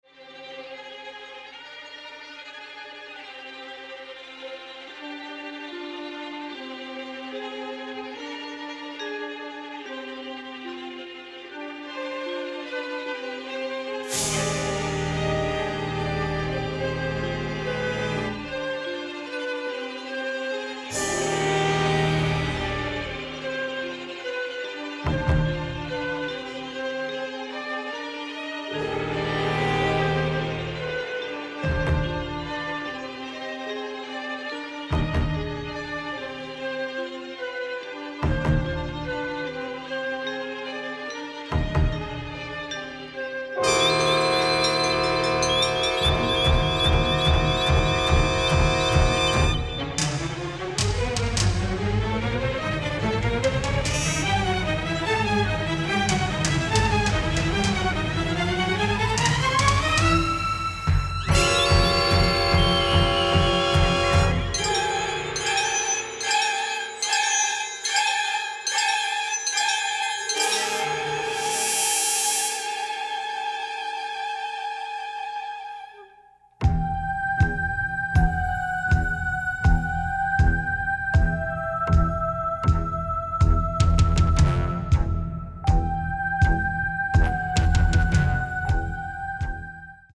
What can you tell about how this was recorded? stereo presentation